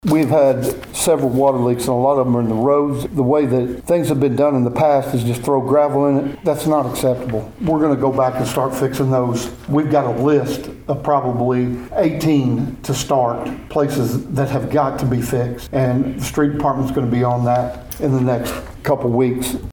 During Tuesday evening's city council meeting in Pawhuska, City Manager Jerry Eubanks talked about the streetscape project and why cars have had to put up with a speedbump with the traffic narrowed to one lane.